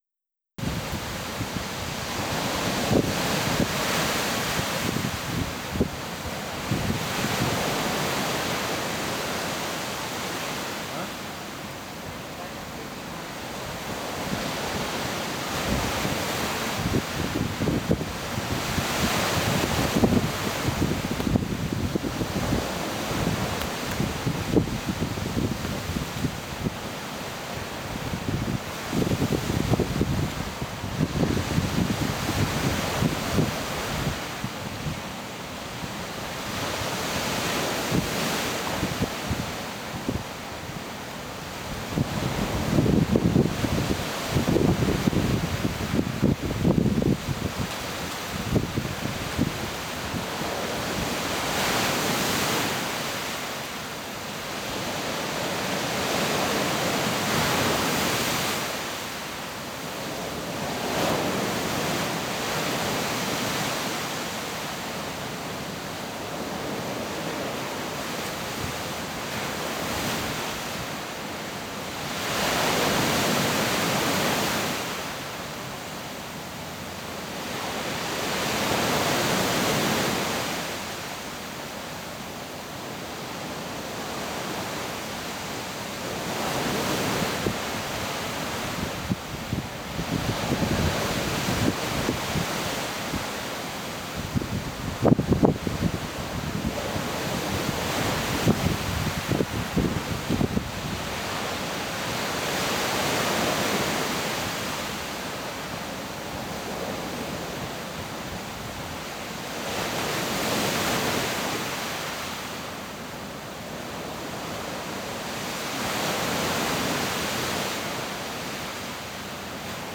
Wellen am Strand
2019-12-28 10 uhr am Strand.flac
Bangkok - Koh Tao (Fieldrecording Audio, Foto, Video)
Audio: aufgenommen mit Zoom H6 + Rode NT4 (24Bit/48kHz)